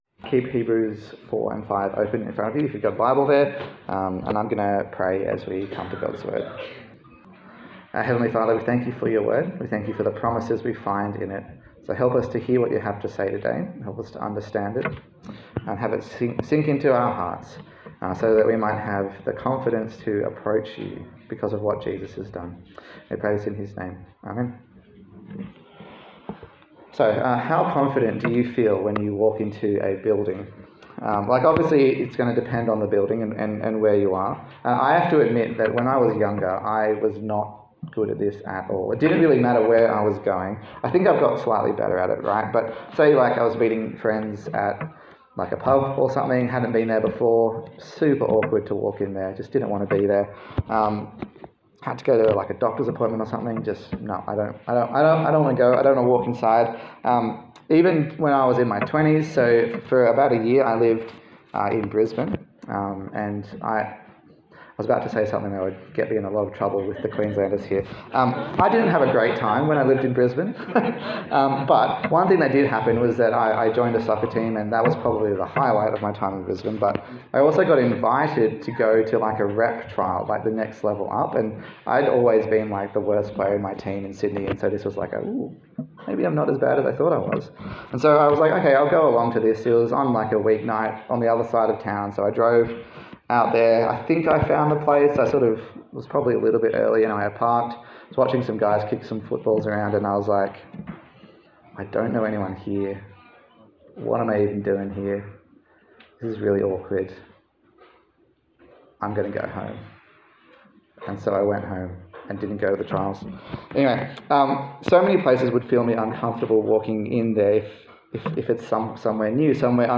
A sermon on the letter to the Hebrews
Service Type: Sunday Service